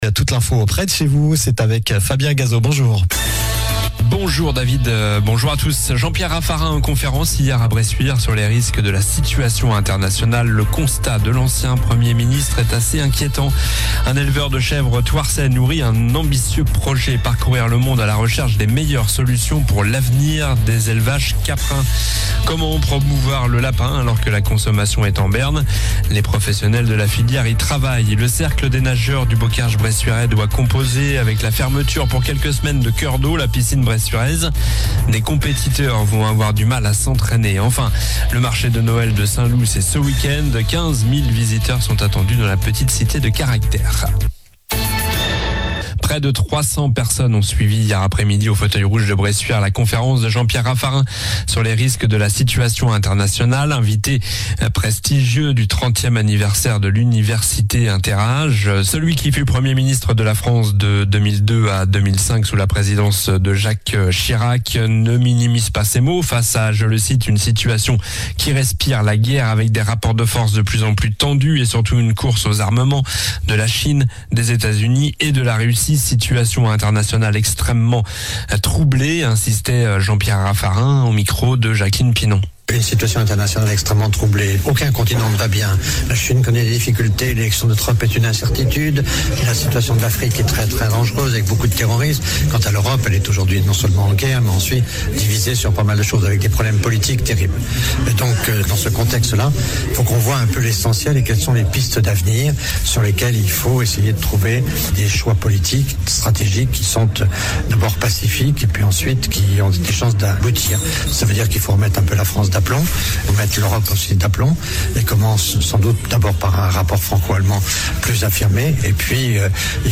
Journal du vendredi 29 novembre (midi)